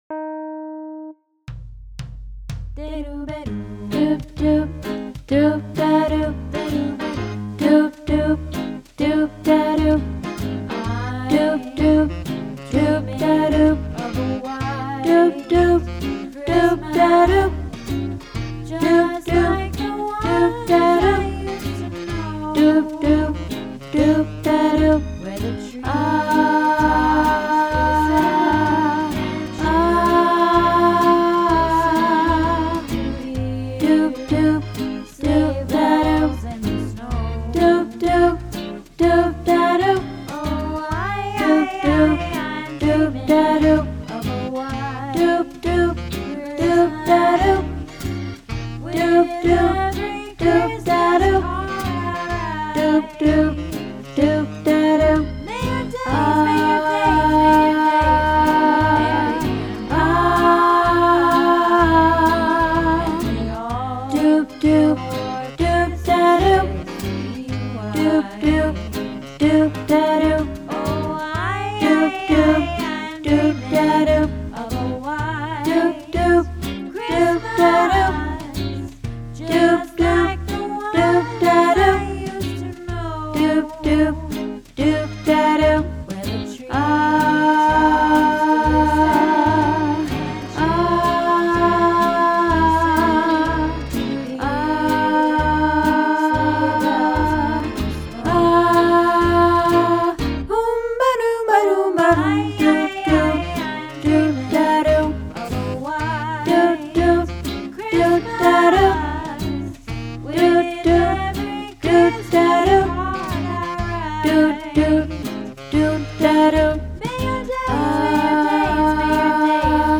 White Christmas - Soprano